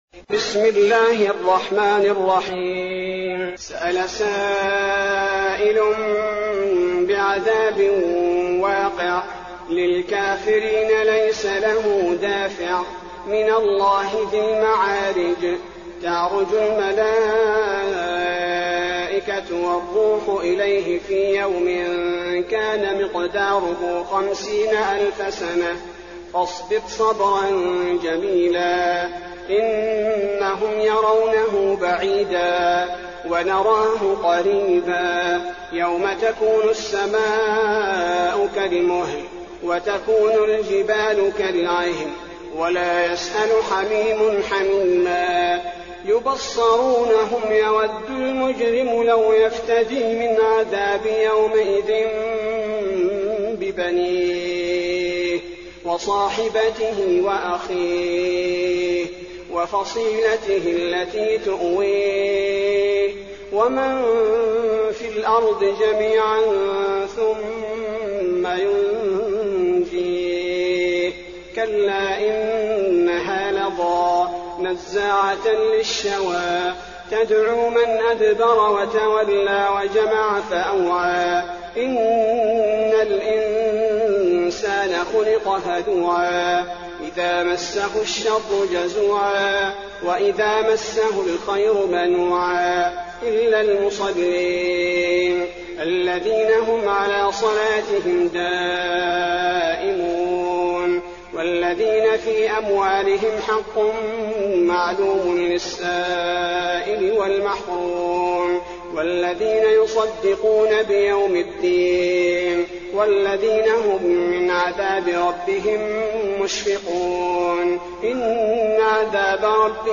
المكان: المسجد النبوي المعارج